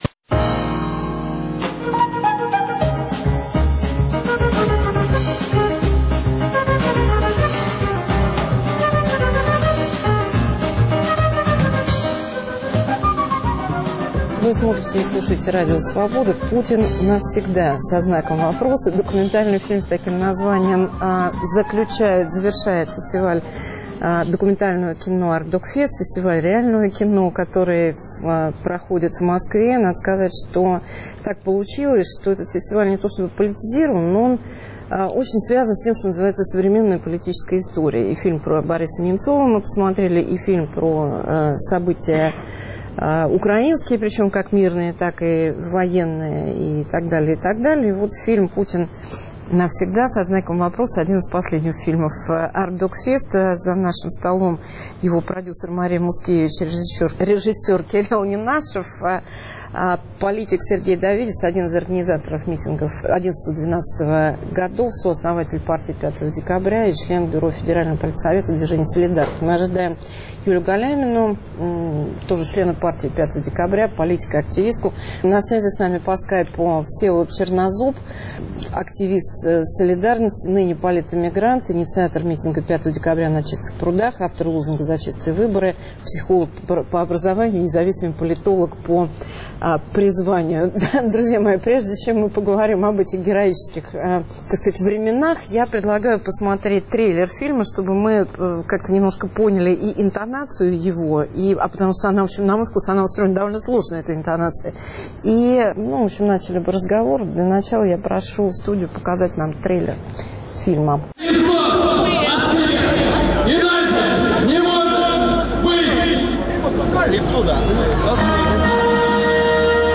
О новых конструкциях российской власти и уроках протестного движения – с документалистами и политическими активистами. Фильм "Путин навсегда?" на Артдокфесте.